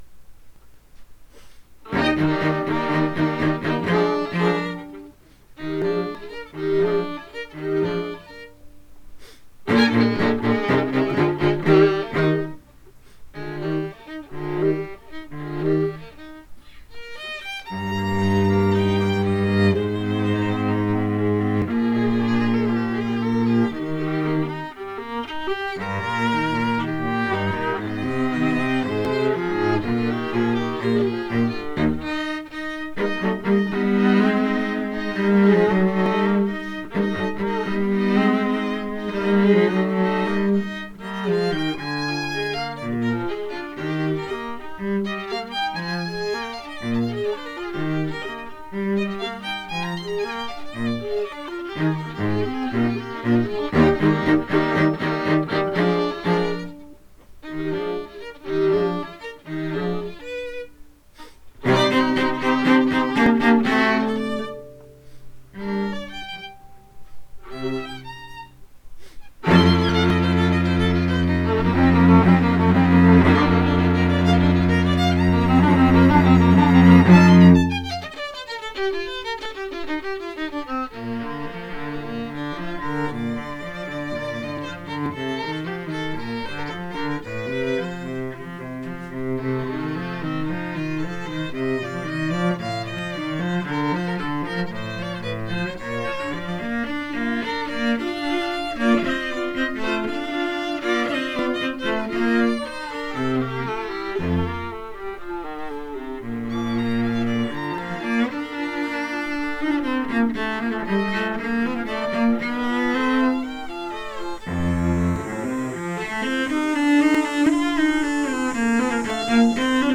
the students
Chamber, Choral & Orchestral Music
Allegro con brio